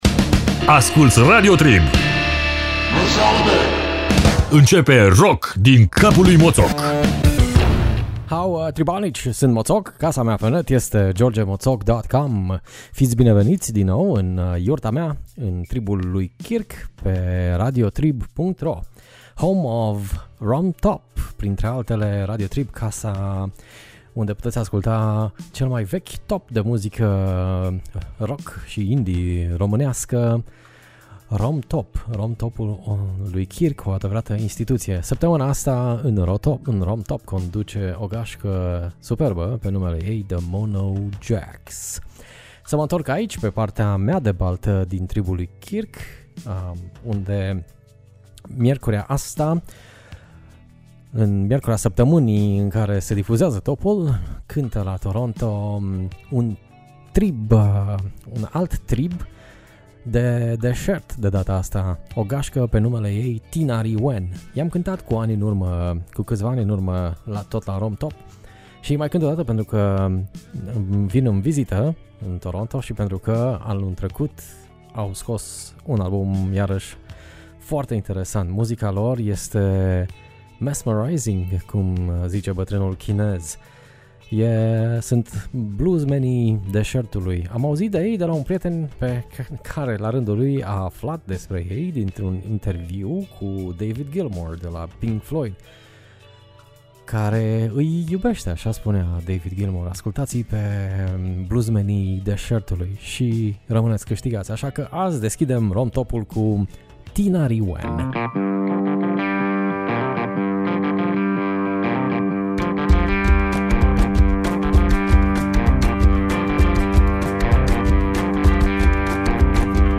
Tribalnici berberi si rockeri nordici la editia de Pasti 2017.